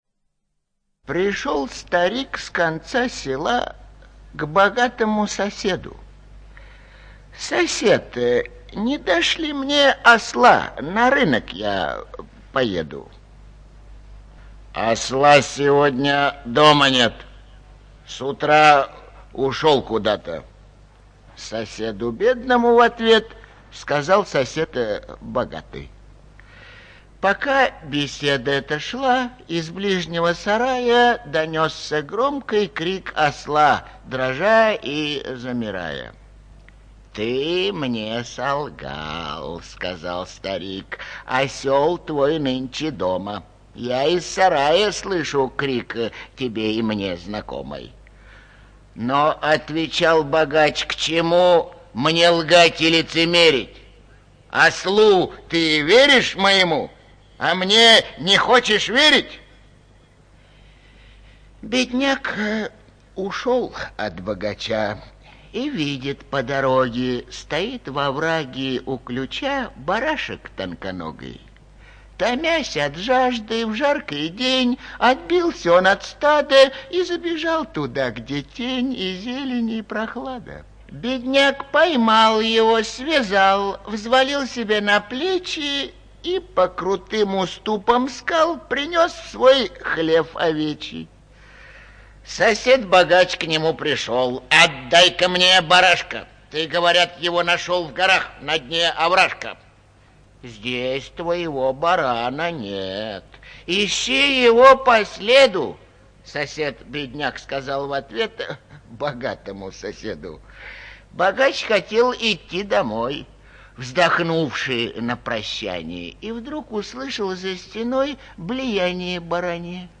ЧитаетИльинский И.